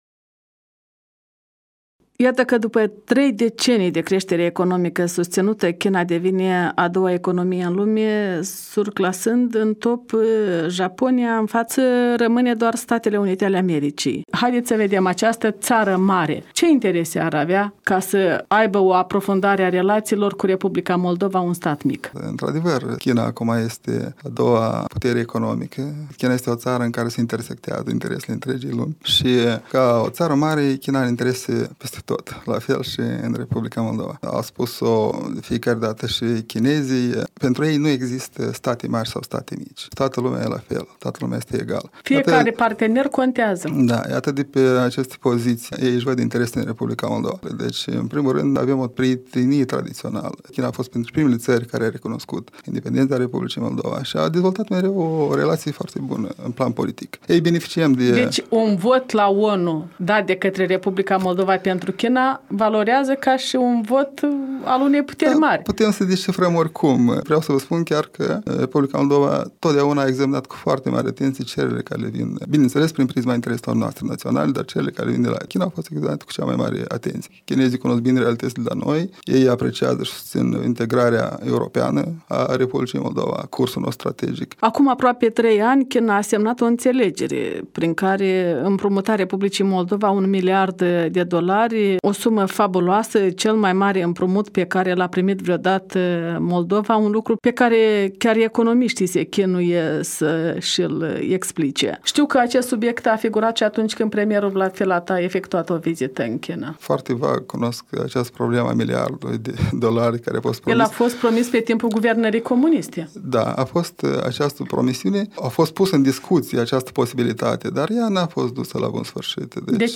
Ambasadorul R.Moldova în China, Anatol Urecheanu